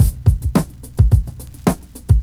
Drum loops
Original creative-commons licensed sounds for DJ's and music producers, recorded with high quality studio microphones.
95 Bpm Fresh Drum Groove A Key.wav
72-bpm-fresh-drum-beat-a-key-9Zd.wav